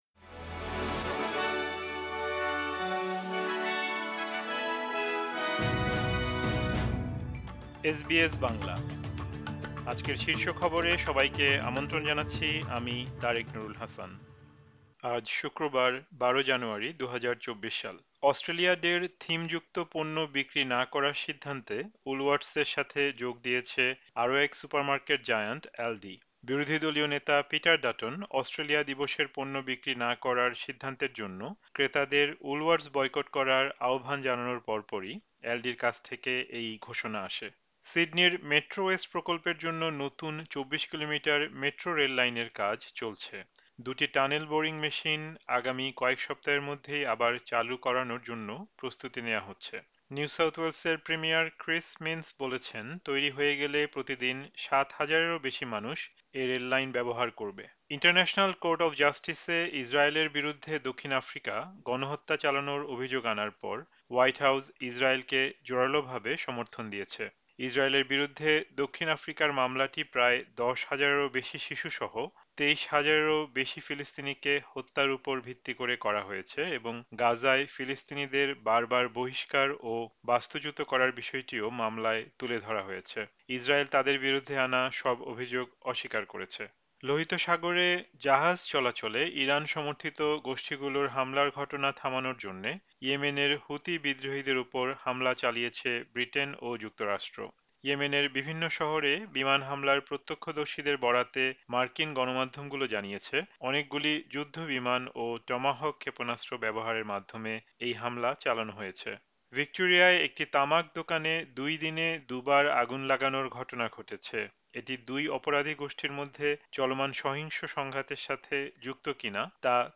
এসবিএস বাংলা শীর্ষ খবর: ১২ জানুয়ারি, ২০২৪